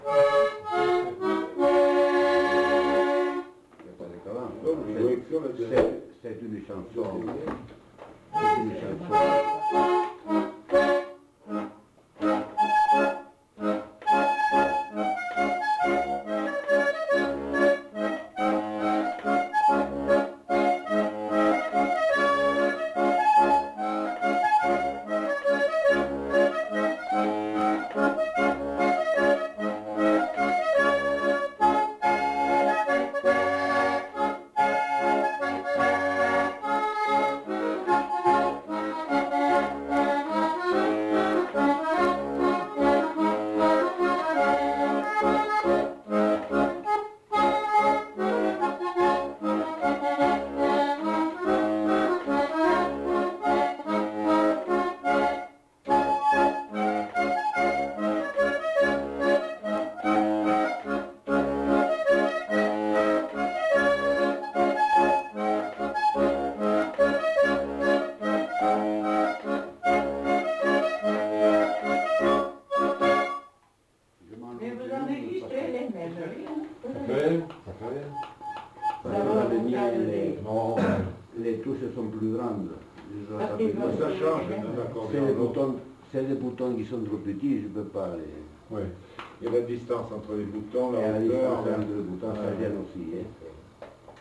Chants et musiques à danser de Bigorre interprétés à l'accordéon diatonique
enquêtes sonores
Marche